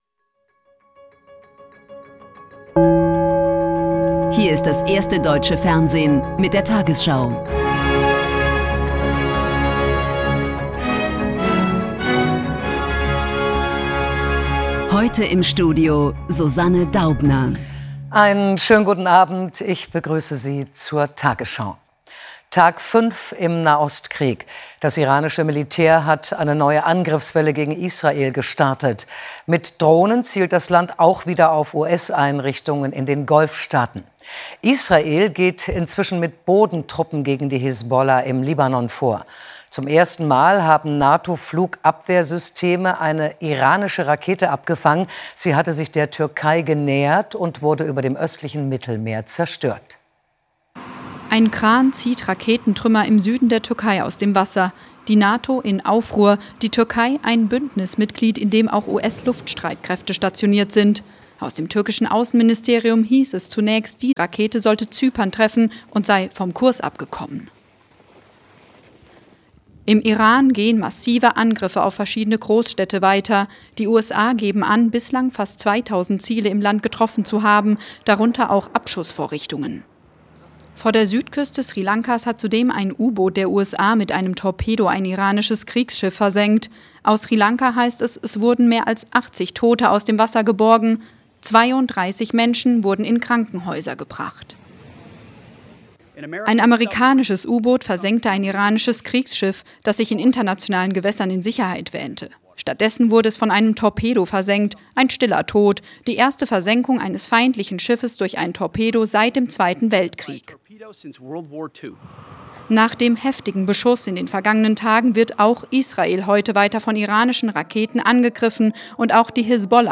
tagesschau 20:00 Uhr, 04.03.2026 ~ tagesschau: Die 20 Uhr Nachrichten (Audio) Podcast